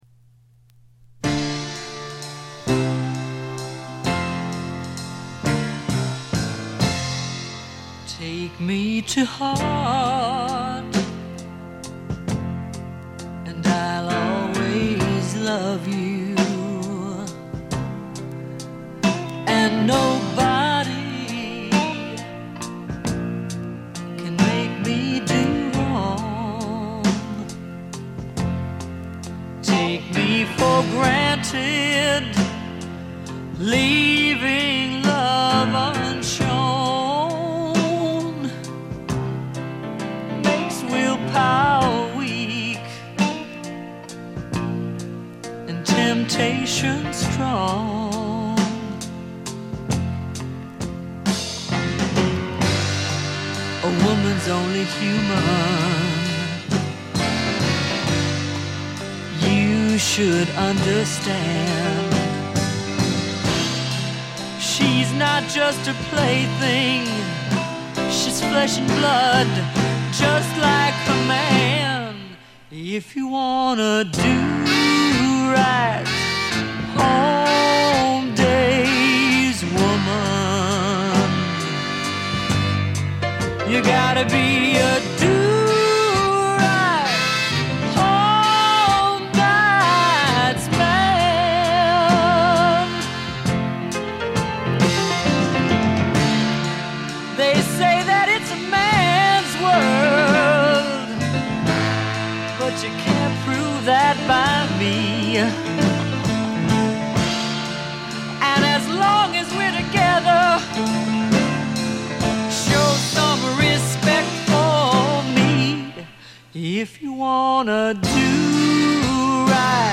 ほとんどノイズ感無し。
白ラベルのプロモ盤。モノ・プレス。
試聴曲は現品からの取り込み音源です。
Recorded At - Muscle Shoals Sound Studios